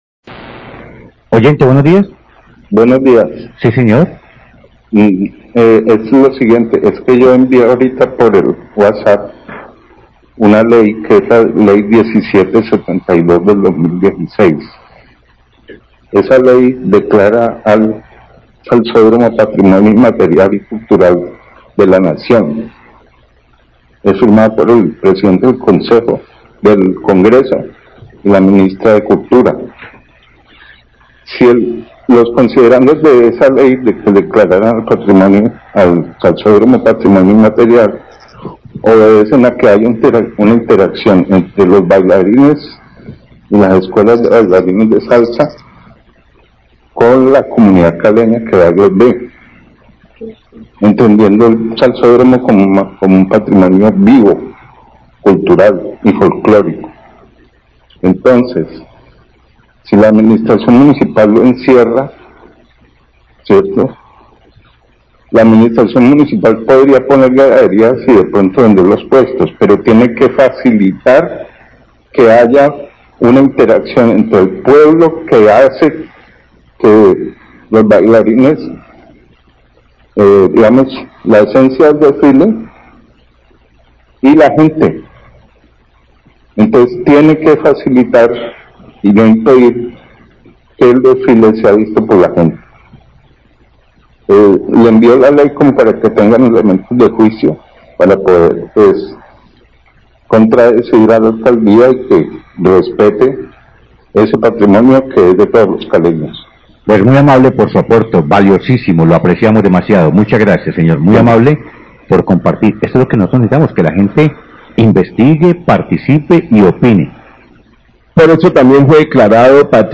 Radio
Oyente